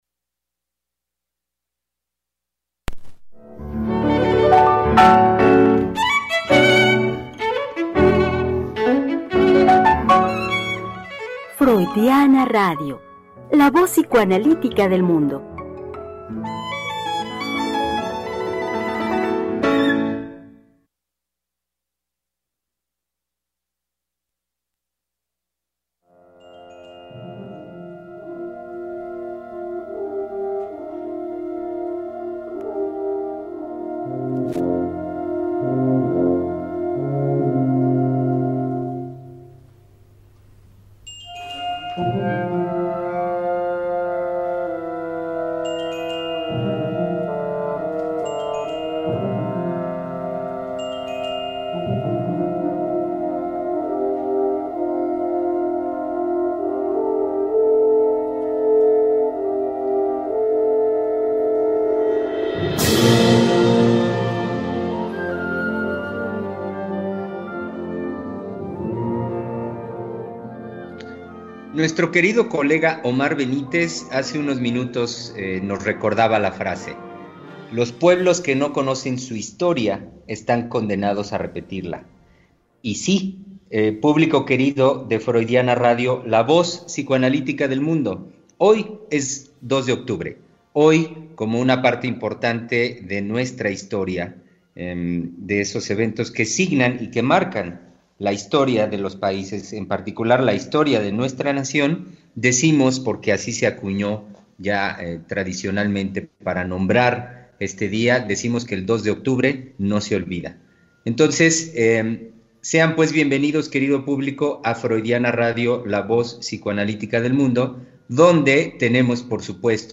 Conversación con nuestros invitados los psicoanalistas del CIEL.